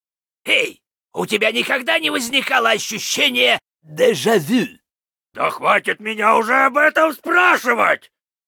Вместо этого предлагаем ознакомиться с новыми звуковыми файлами из игрового клиента Heroes of the Storm, добавленными вместе с новым героем — Потерявшимися Викингами.
LostVikingsBase_Pissed08.ogg